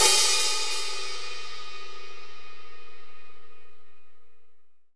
Index of /90_sSampleCDs/AKAI S6000 CD-ROM - Volume 3/Hi-Hat/AMBIENCE_HI_HAT_2